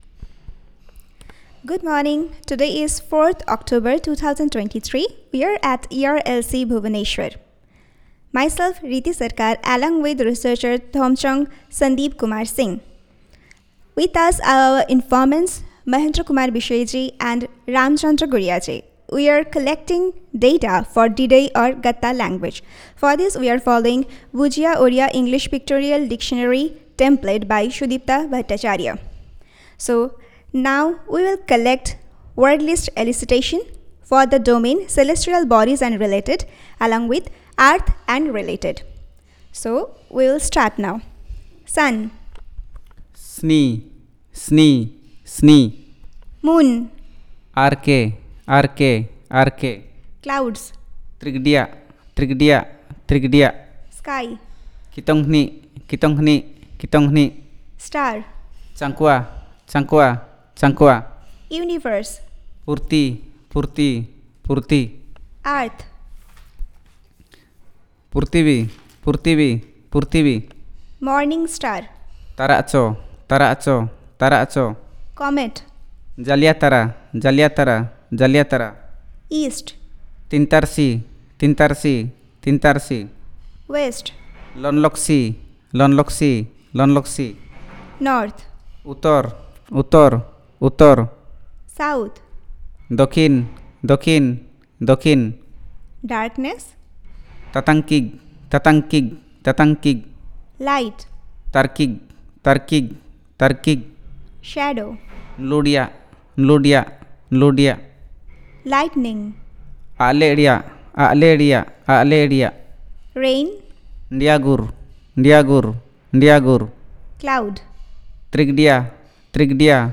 Elicitation of words on Celestial bodies and related